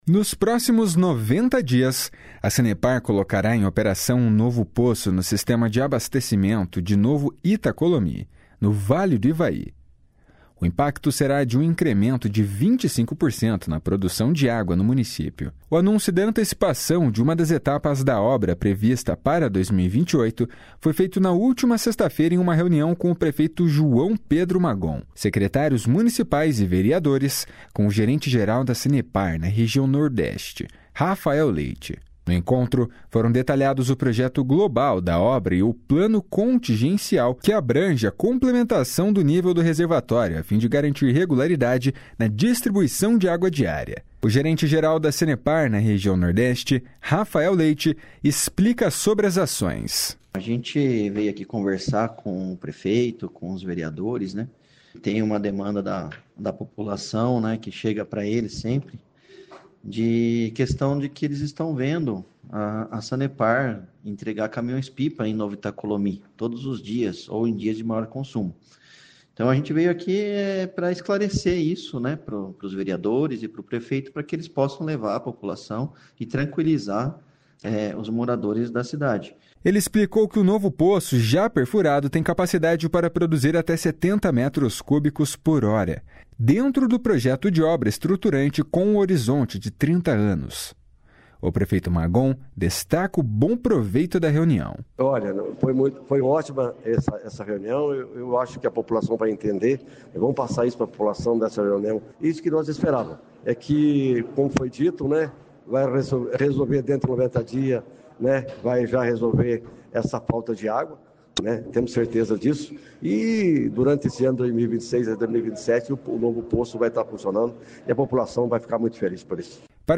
O prefeito Magon, destaca o bom proveito da reunião.